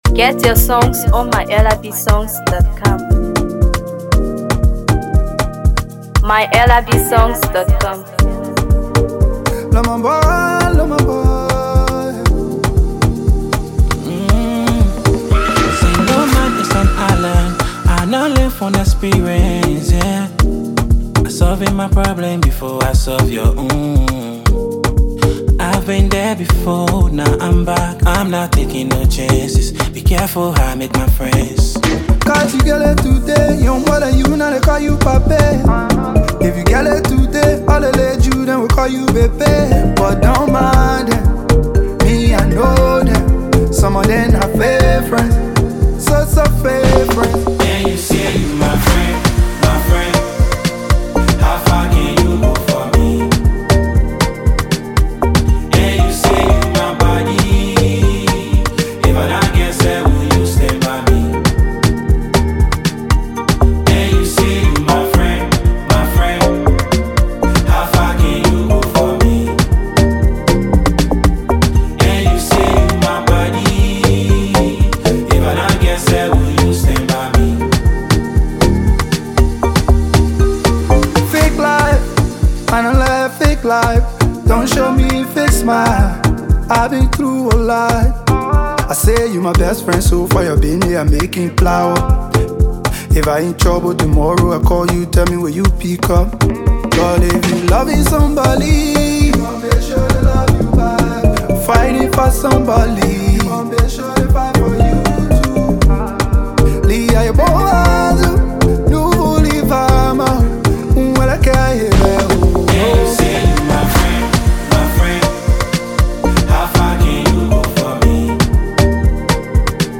Afrobeat
Packed with vibrant afrobeat rhythms and heartfelt lyrics